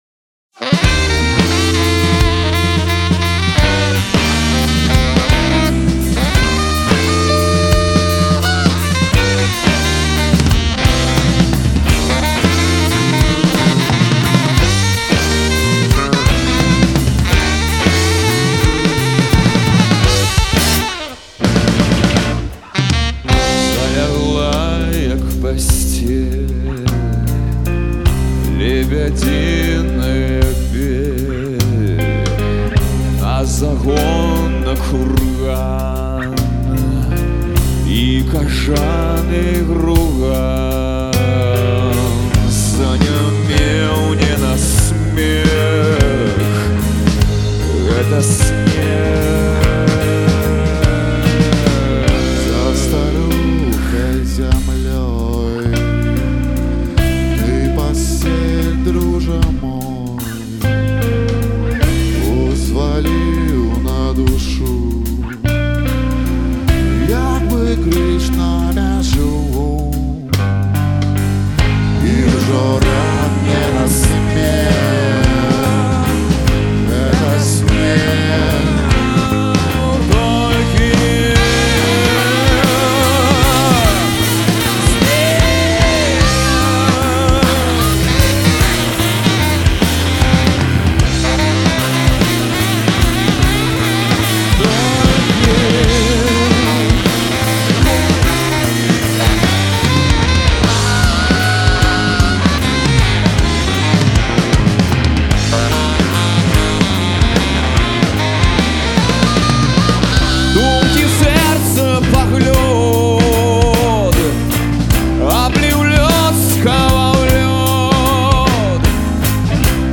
у дуэце з найлепшым саксафаністам Беларусі